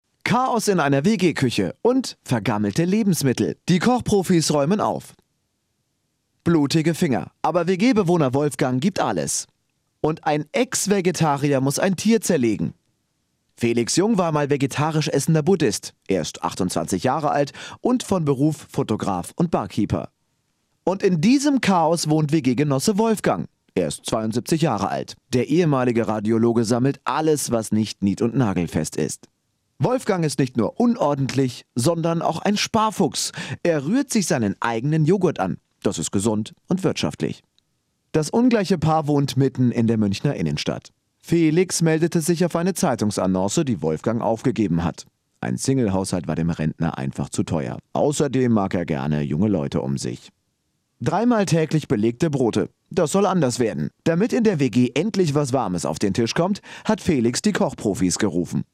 deutscher Profi-Sprecher. TV, Radio, Werbung, Synchron
Kein Dialekt
Sprechprobe: Industrie (Muttersprache):
german voice over artist